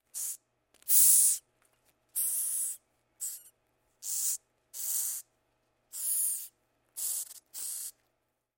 В коллекции есть разные варианты: от шороха лапок до стрекотания.
Звук шипения редкого таракана